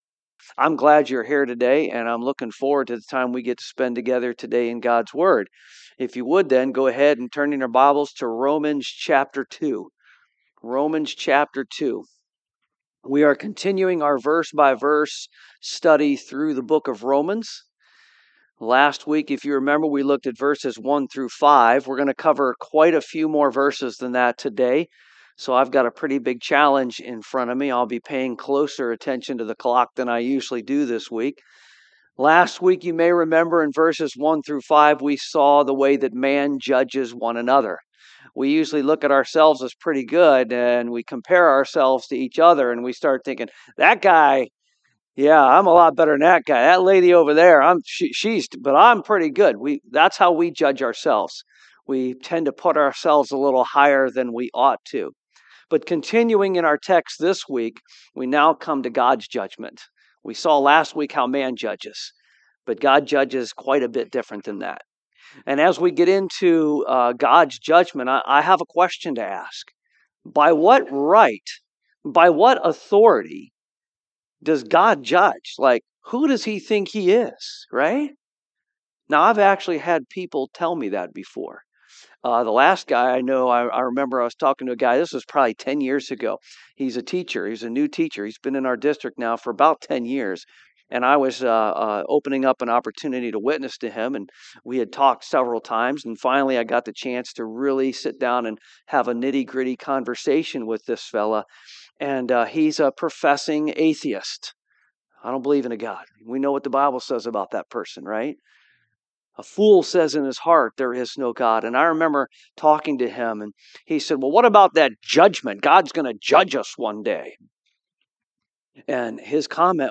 Preacher
Service Type: AM